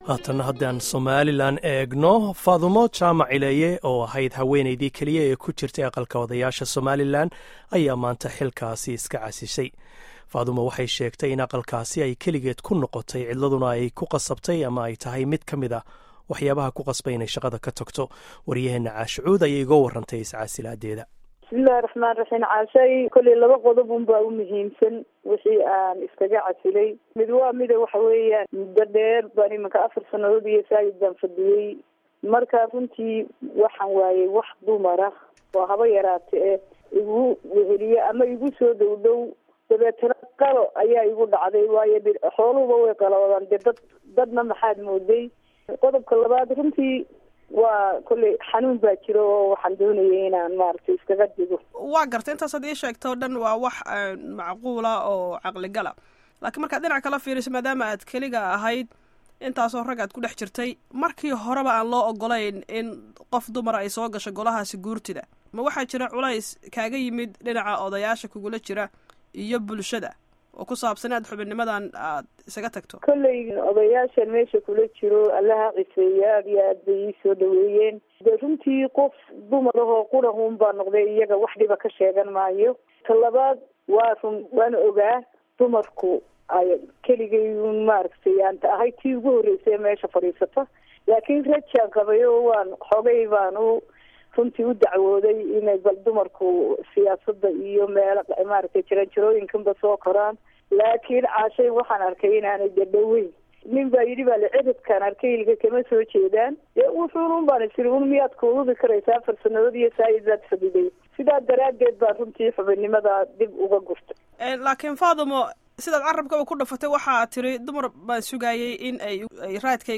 Dhageyso wareysiga Faadumo Ileeye